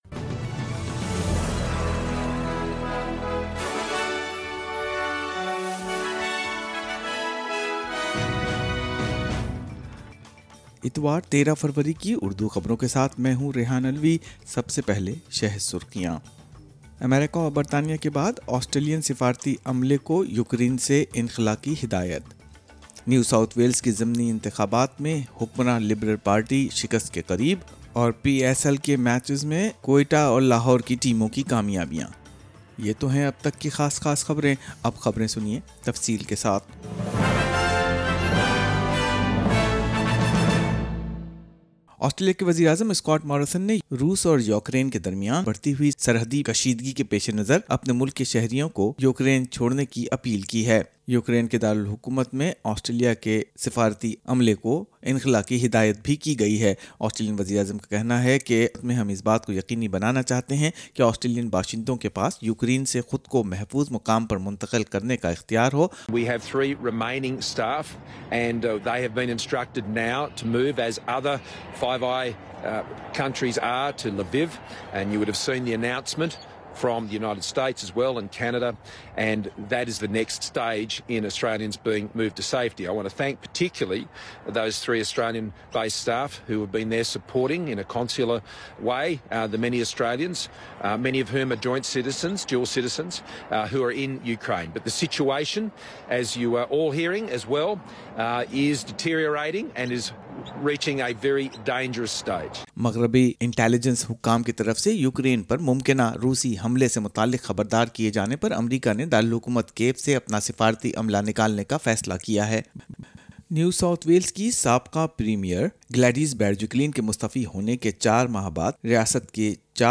Urdu News Sunday 13 February 2022 - A swing against NSW Liberal in three out of four bye Elections